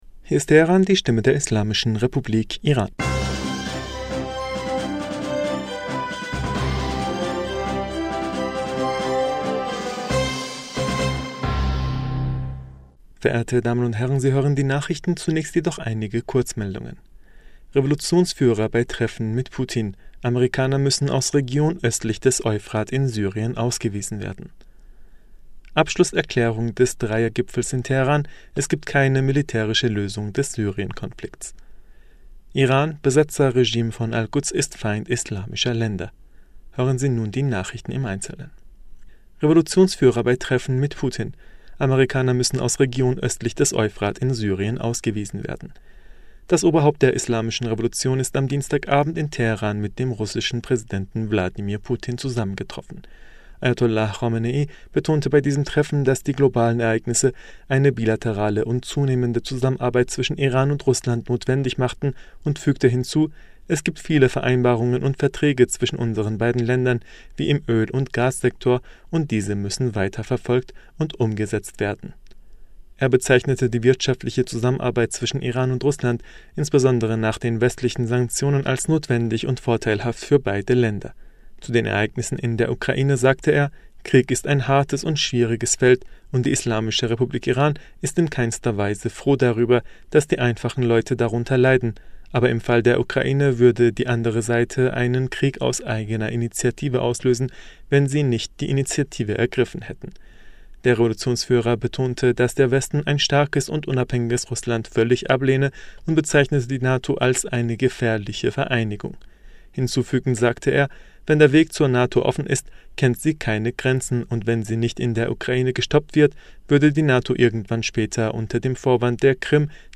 Nachrichten vom 20. Juli 2022